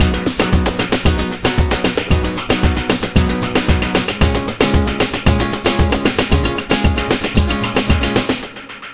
Ala Brzl 3 Full 3b-G#.wav